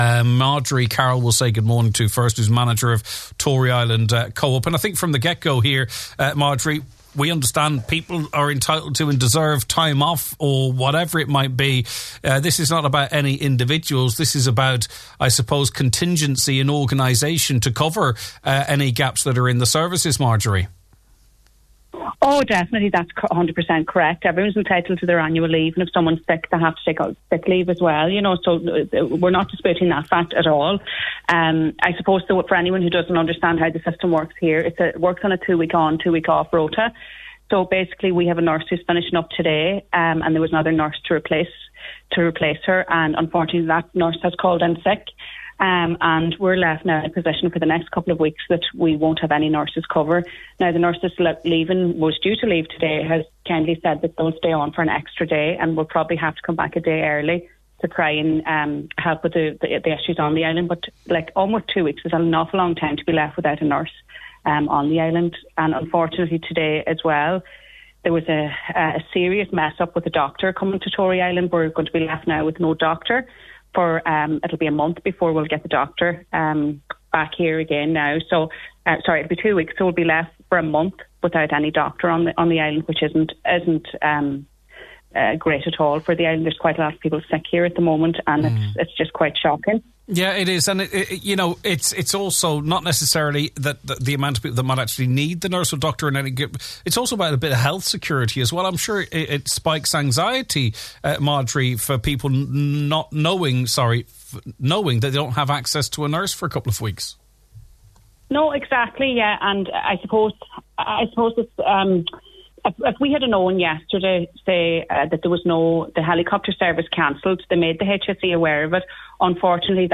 On today’s Nine til Noon Show, she said this feeds into a sense on the island that they are effectively on their own………